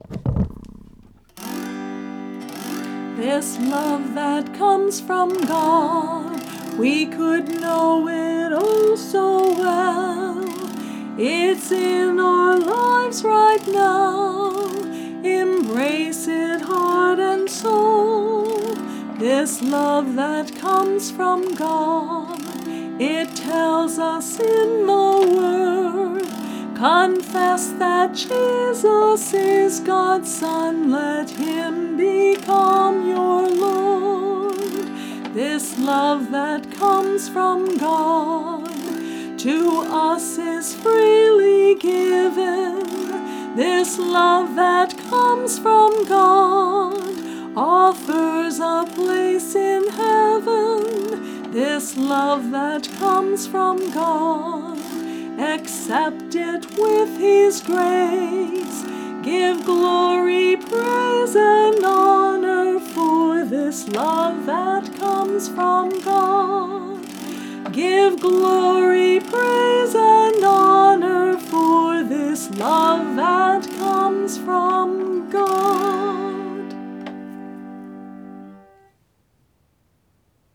This entry was posted in Christian Music, Lent, Uncategorized and tagged , , , , , , , , , .